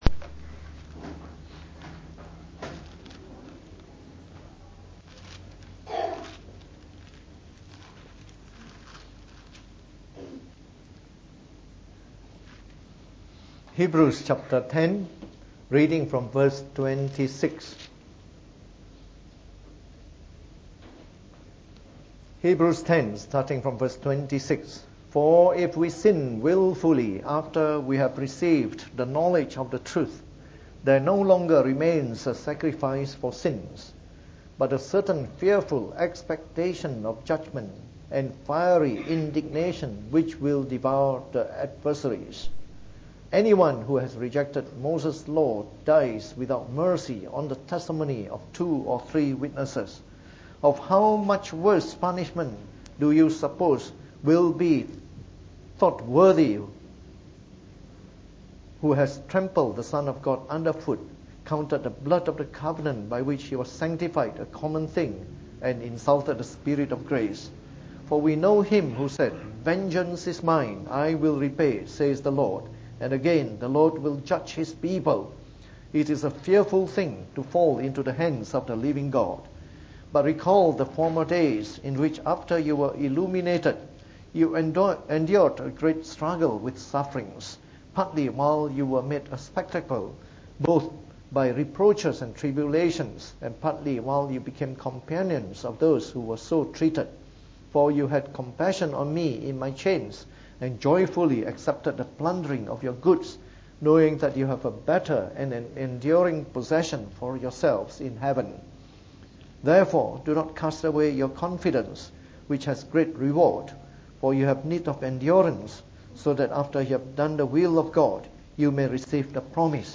From our series on the “Epistle to the Hebrews” delivered in the Evening Service.